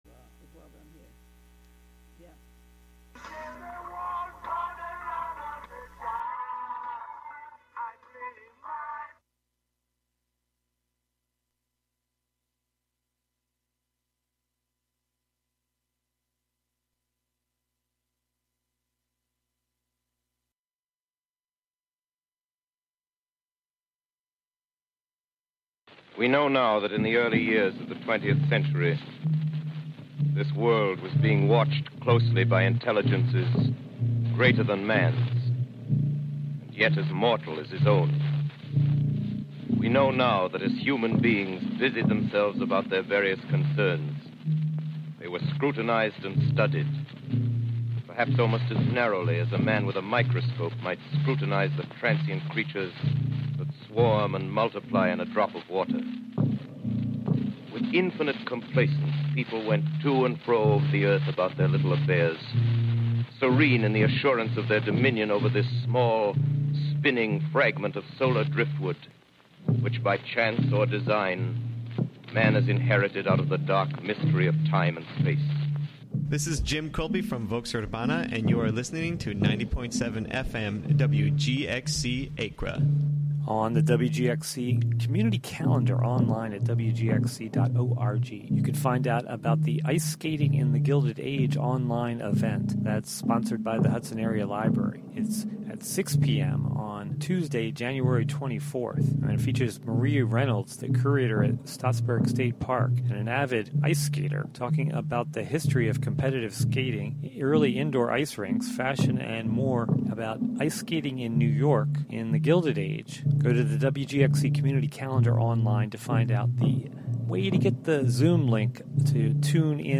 On this monthly program, “La Ville Inhumaine” (The Inhuman City), you will hear music, found sounds, words, intentional noise, field recordings: altogether, all at once.